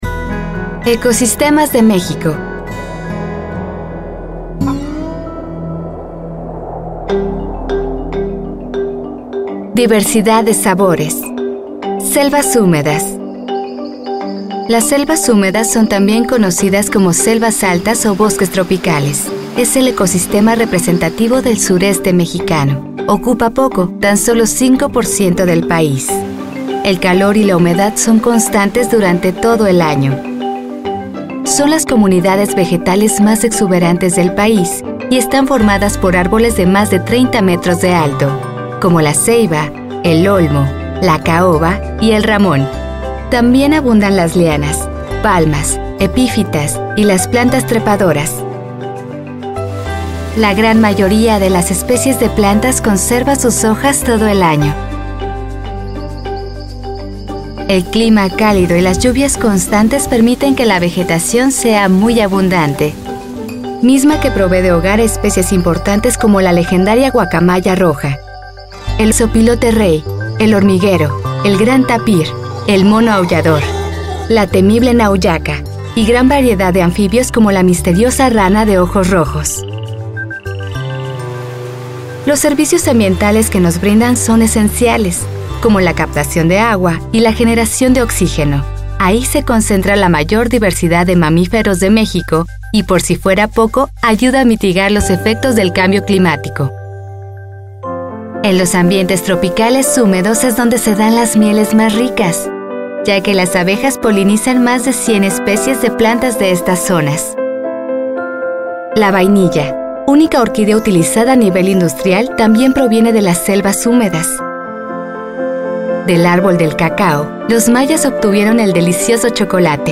Kommerziell, Natürlich, Cool, Vielseitig, Corporate
Unternehmensvideo
Erklärvideo